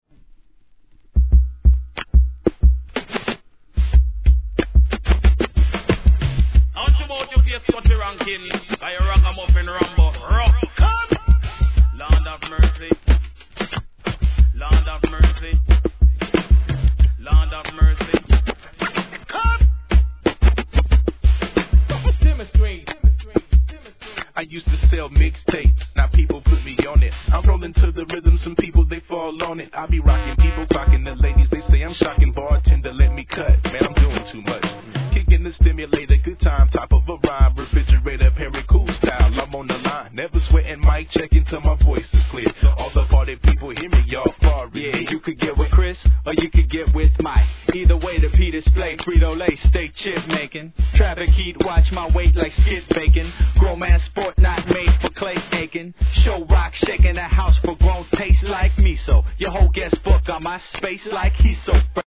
1. HIP HOP/R&B
シンプルなBEATにOLD SCHOOLなスクラッチが、なんともCHEEPでかっけ〜す!さすが分かっております!!